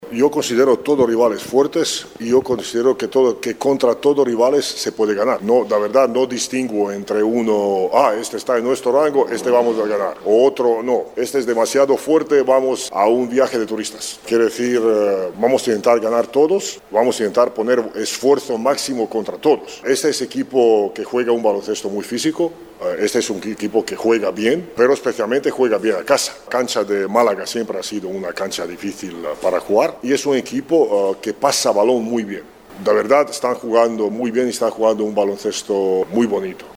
L’entrenador del MoraBanc Andorra, Zan Tabak, ha remarcat la dificultat del duel, però ha insistit que es pot guanyar a qualsevol rival.